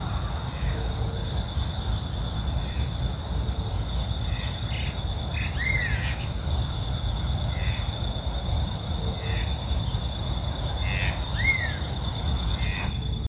Tucán Pico Verde (Ramphastos dicolorus)
Nombre en inglés: Red-breasted Toucan
Localidad o área protegida: Bio Reserva Karadya
Condición: Silvestre
Certeza: Fotografiada, Vocalización Grabada
Tucan-pico-verde.mp3